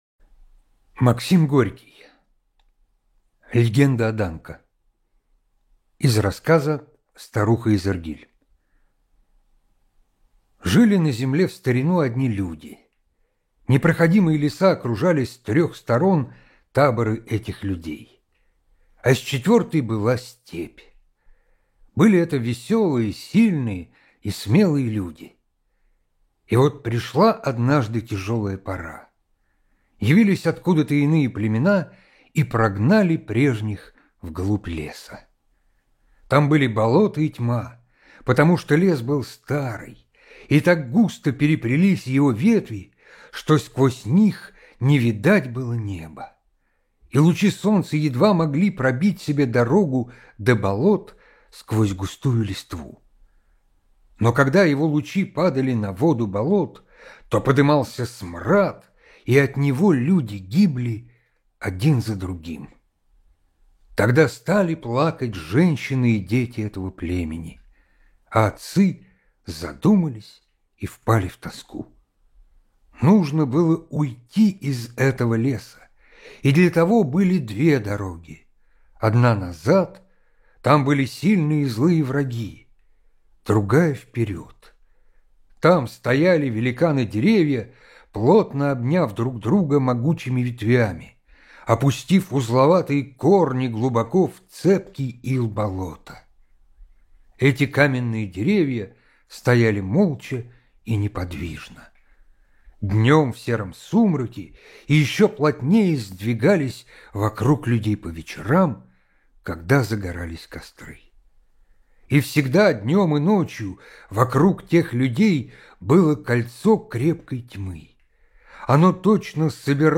Легенда о Данко - аудио рассказ Горького - слушать онлайн